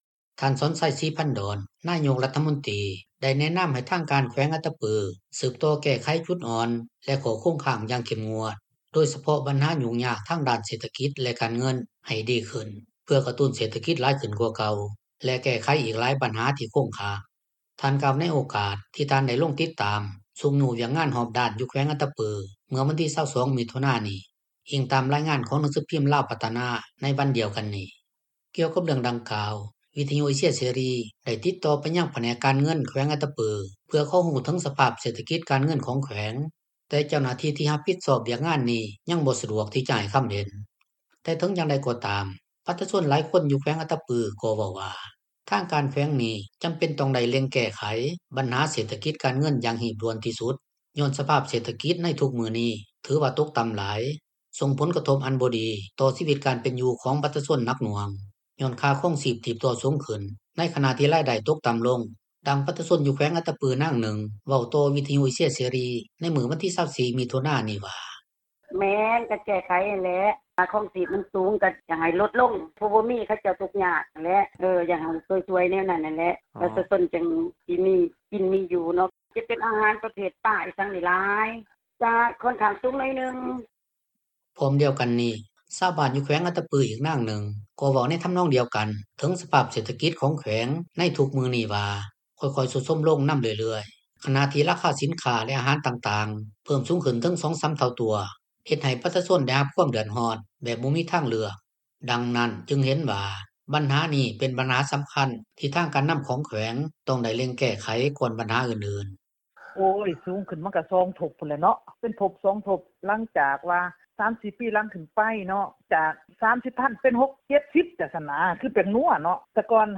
ດັ່ງປະຊາຊົນຢູ່ແຂວງອັດຕະປືນາງນຶ່ງ ເວົ້າຕໍ່ວິທຍຸເອເຊັຽເສຣີ ໃນມື້ວັນທີ 24 ມິຖຸນານີ້ວ່າ: